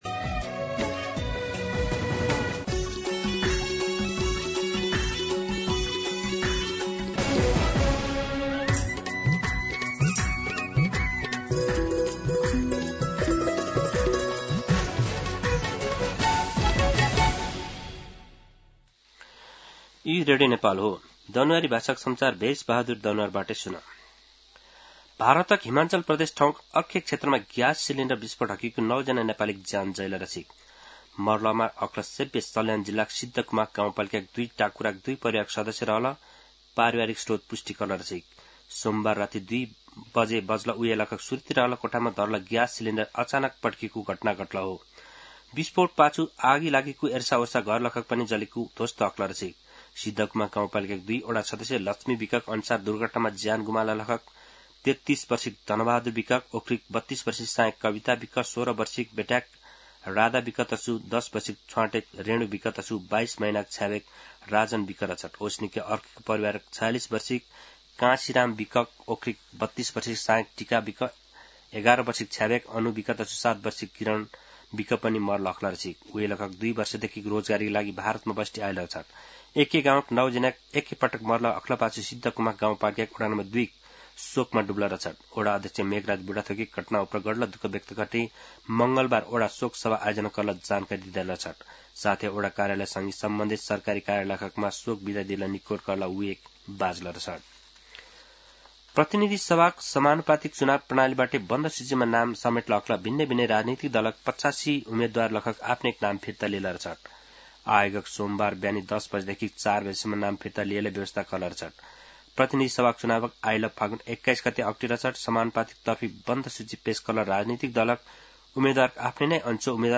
दनुवार भाषामा समाचार : २९ पुष , २०८२
Danuwar-News-09-29.mp3